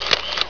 snd_341_Camera Shutter.wav